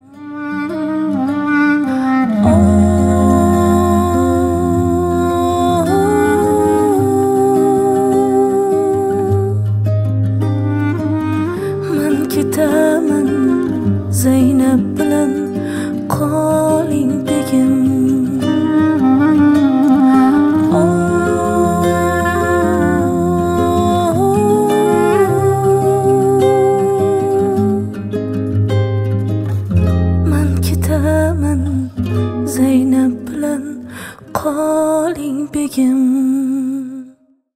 Поп Музыка
кавказские # грустные